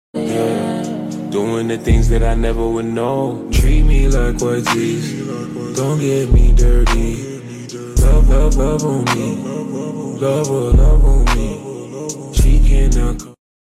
Hehhehehehe sound effects free download